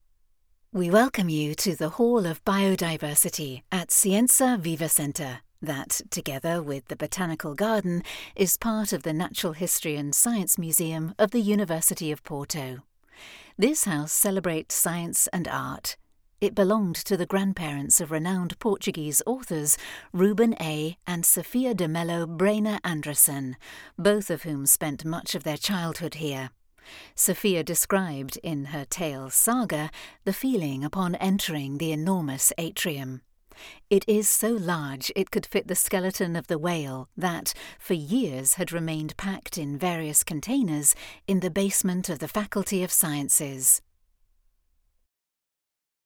Female
British English (Native)
Assured, Authoritative, Confident, Corporate, Engaging, Reassuring, Smooth, Warm, Versatile
My vocal style is clear, warm and rich, with a naturally reassuring quality that inspires trust.
New Commercial Demo.mp3
Microphone: Neumann TLM103